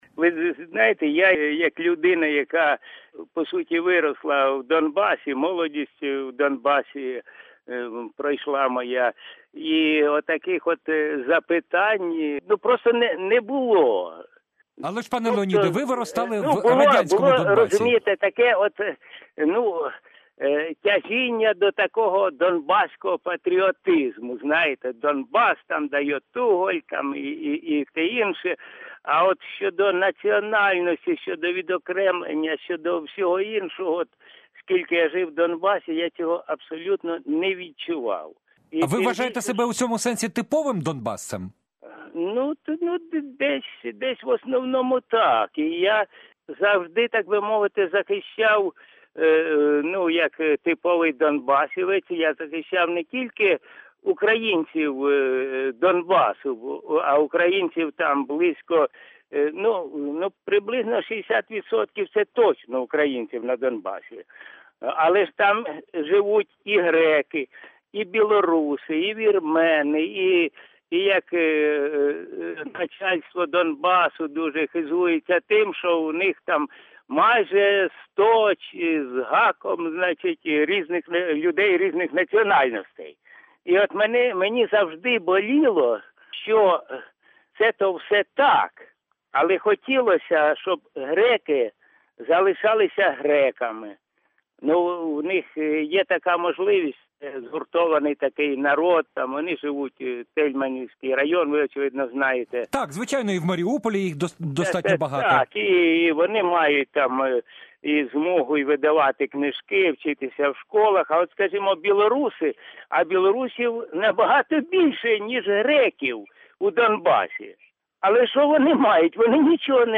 В інтерв'ю Бі-Бі-Сі він говорить зокрема про те, чи хвилюють порушені питання самих донбасівців: